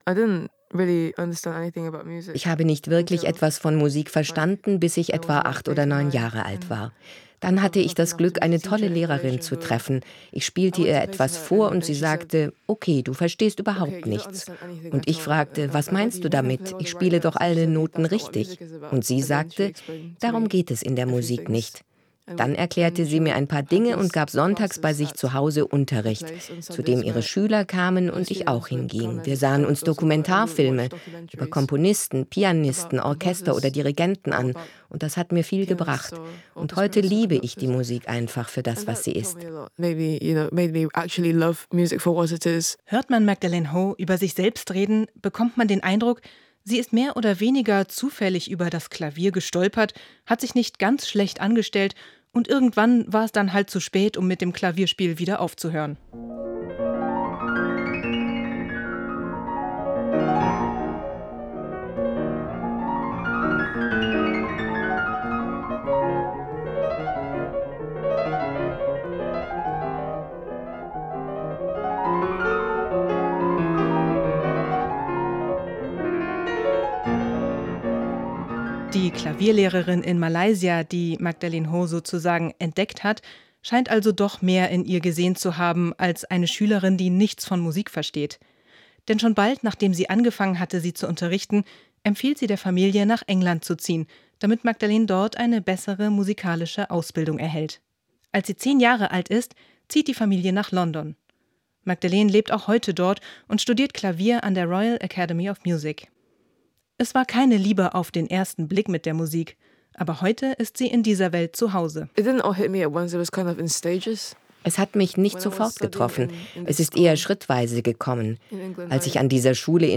Porträt